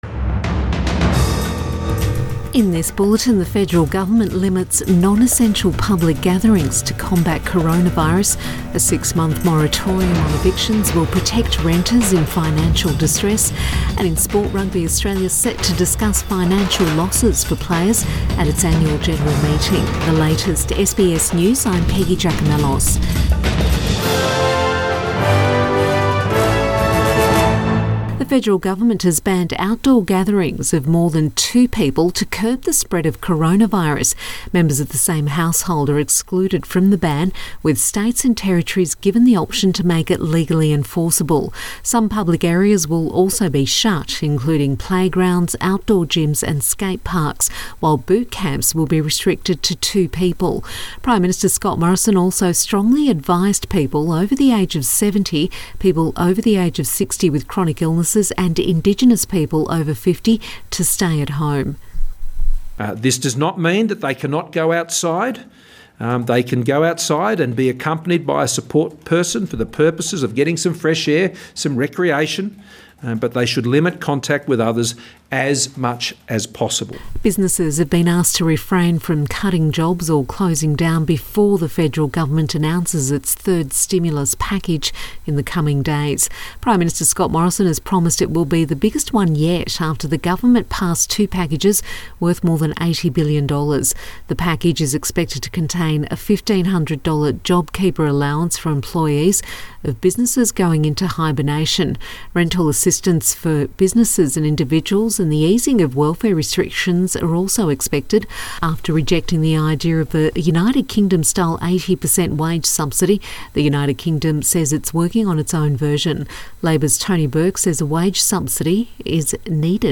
AM bulletin March 30 2020